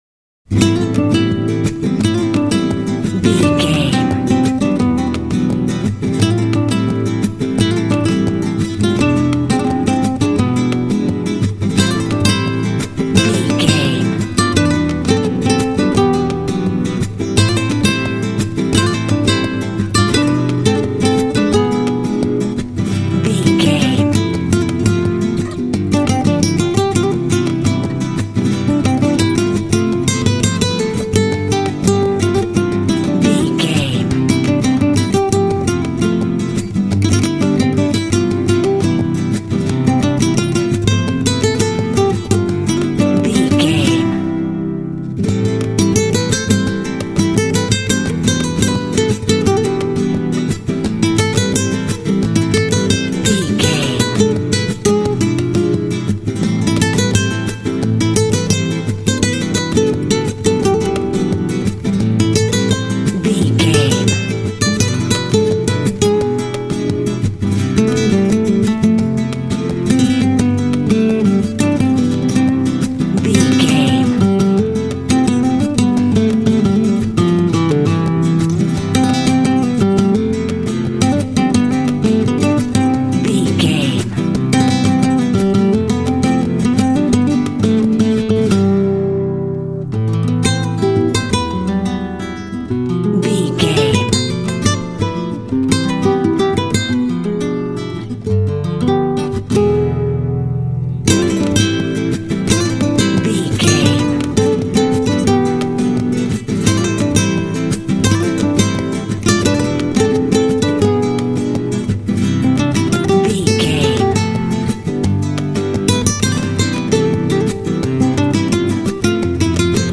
Aeolian/Minor
sexy
smooth
sensual
acoustic guitar
latin
flamenco
mambo
rhumba